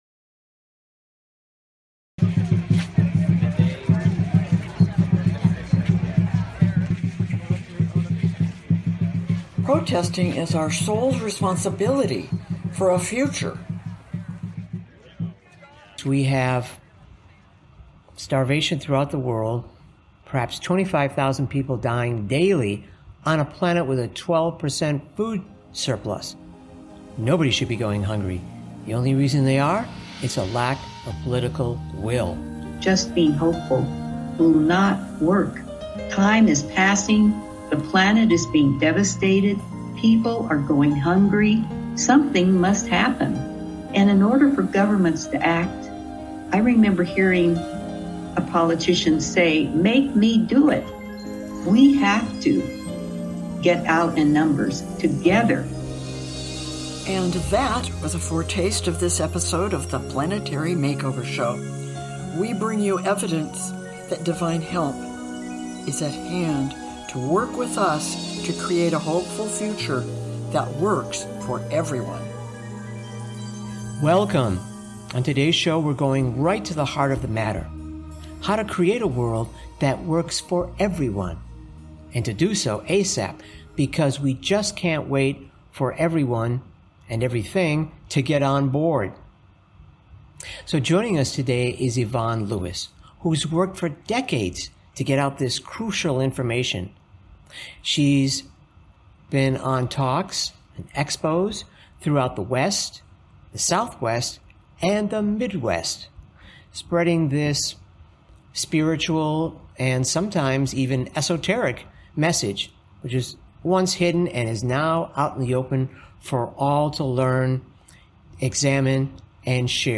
Talk Show Episode, Audio Podcast, Planetary MakeOver Show and Protesting is Our Soul's Responsibility for a Future!